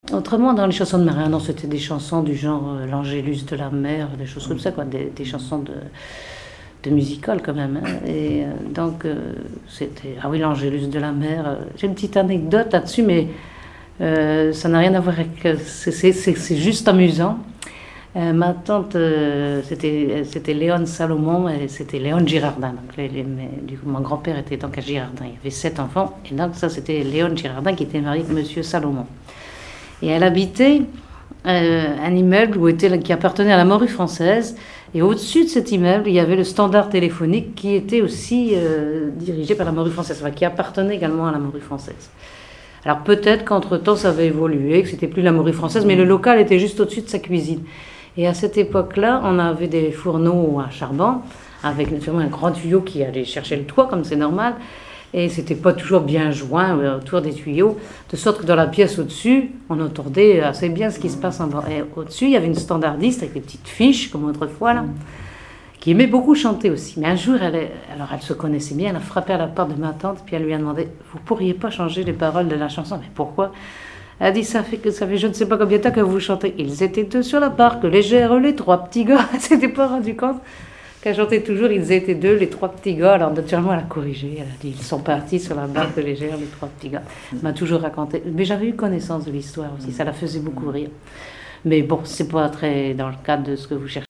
Conversation sur les musiciens de Saint-Pierre et Miquelon
Témoignage